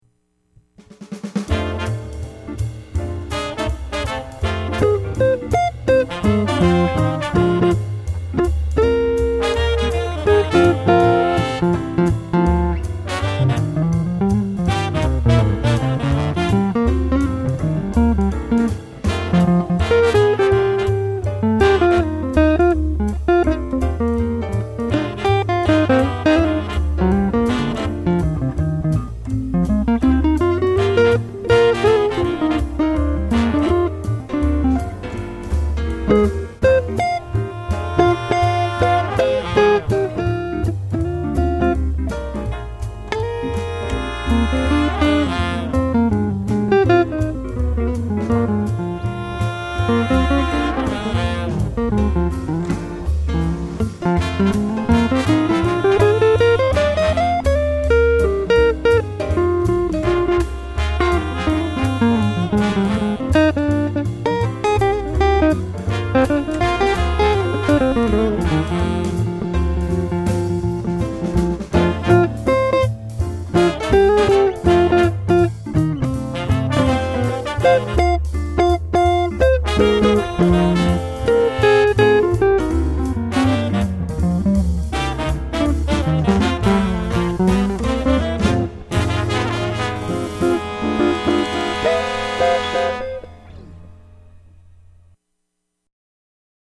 Guitar Works